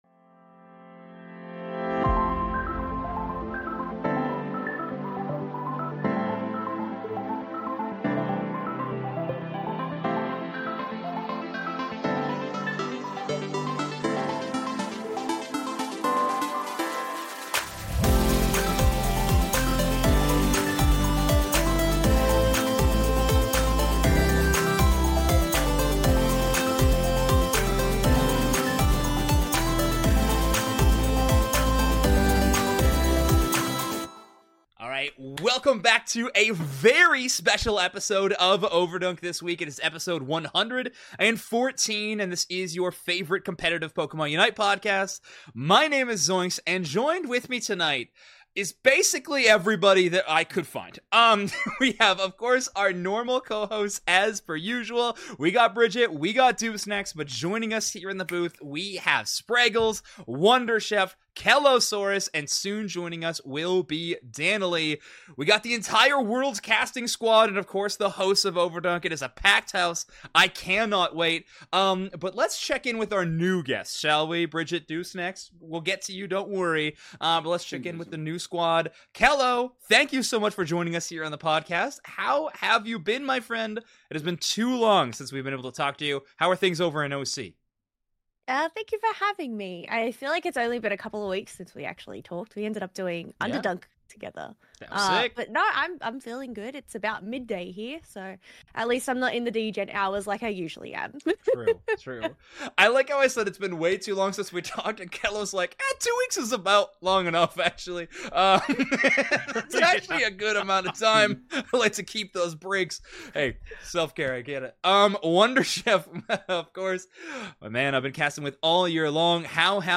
Every single caster for Worlds shows up to talk with us in today's episode!